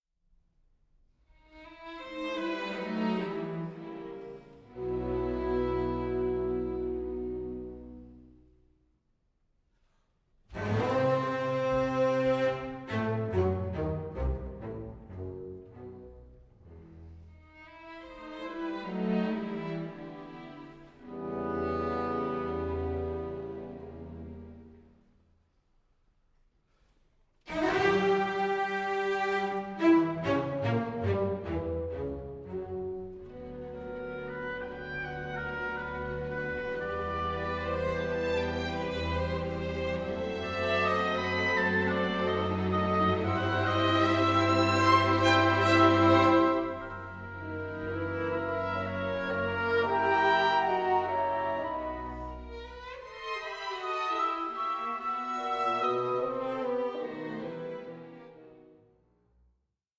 sparkling dialogue between strings and winds